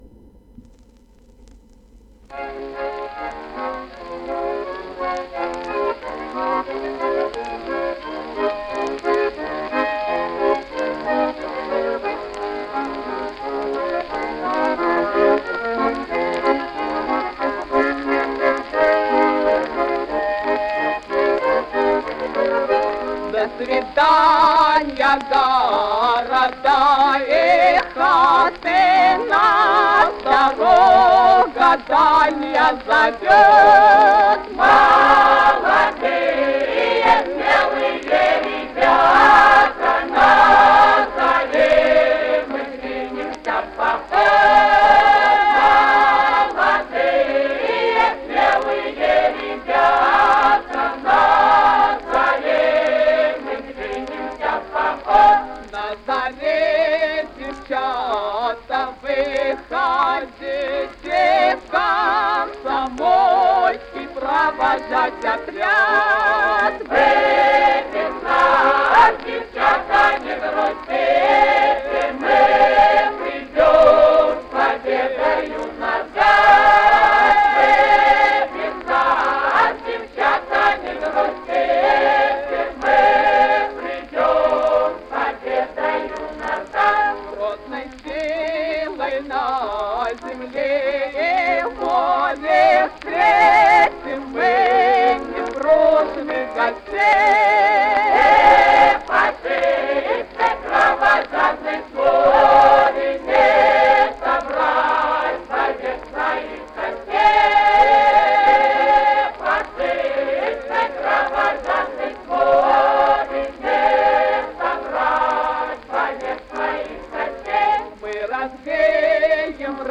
Озвучено с пластинки.